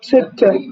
spoken-arabic-digits